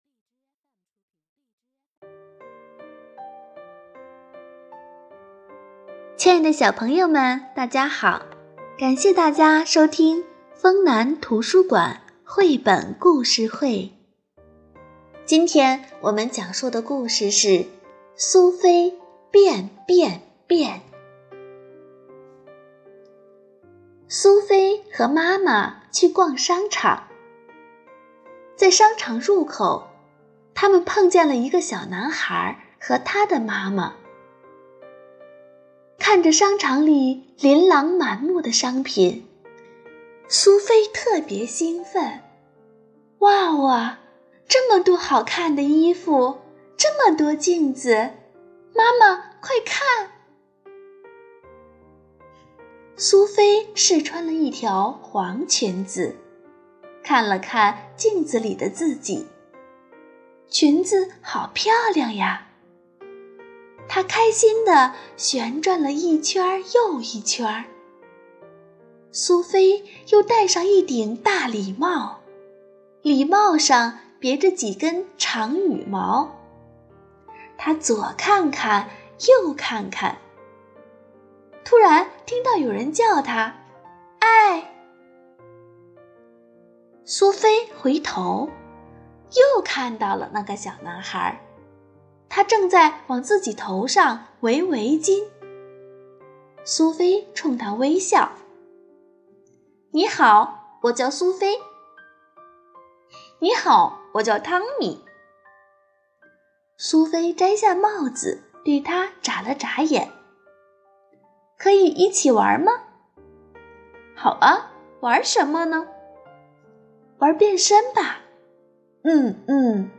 【讲座】《听故事·学育儿》专题讲座五：如何培养儿童的想象力
刚才我们朗诵的绘本《苏菲变变变》讲述了苏菲和汤米在商场里玩的恶搞游戏，他们一会把自己打扮成小丑，一会把自己装扮成大树，一会又变成了狮子的模样，然后又变身成劫匪、恐龙，真是让人哭笑不得。